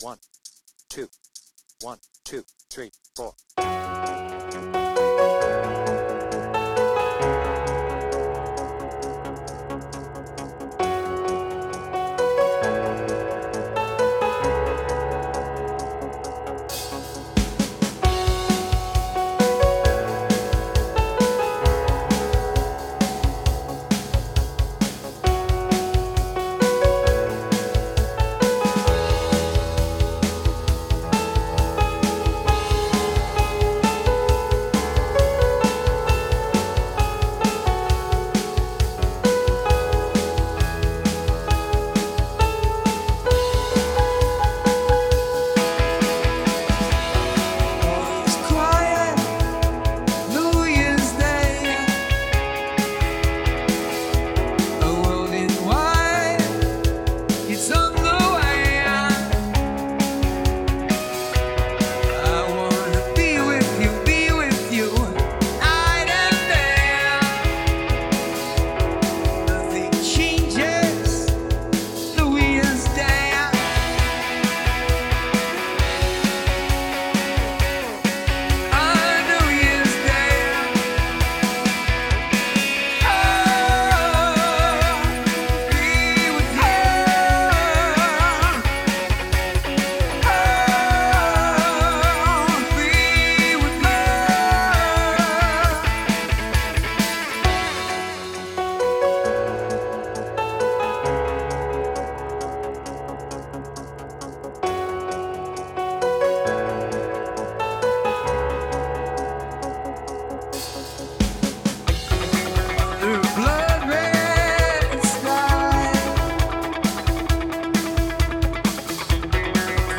BPM : 133
Tuning : D
With vocals
Based on the I+E 2018 Tour